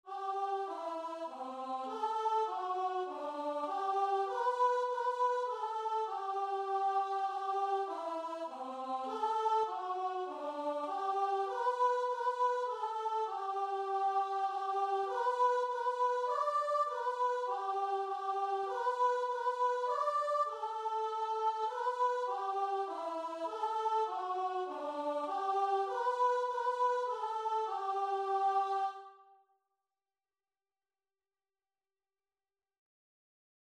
3/4 (View more 3/4 Music)
Classical (View more Classical Guitar and Vocal Music)